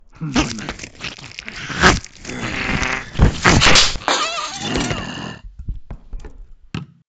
Talking Ben Eating Lab Flush Sound Effect Free Download
Talking Ben Eating Lab Flush